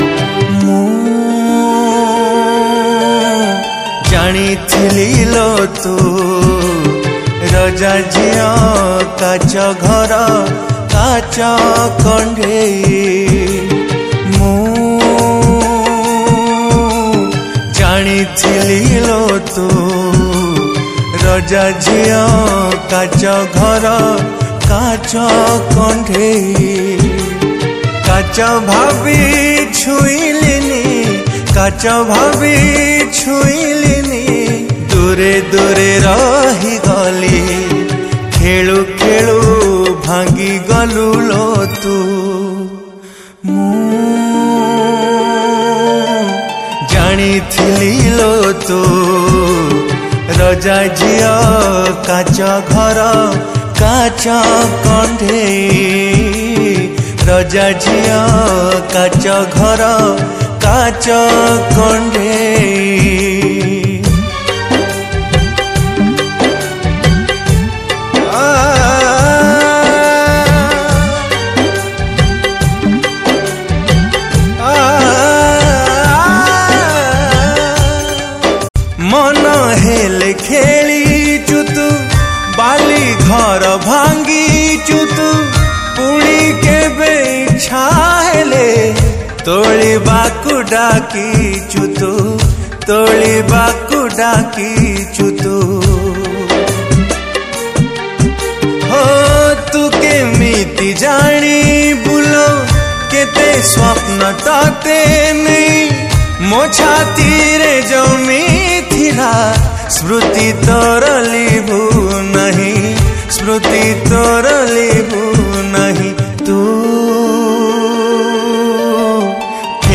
Odia Sad Romantic Songs